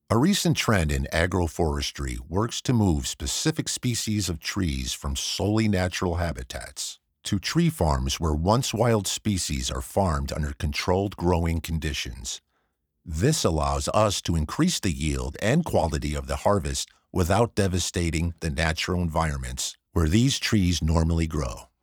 Elearning
neutral accent. middle age to senior.
*A Source Connect equipped professional sound booth.
*Broadcast-quality audio.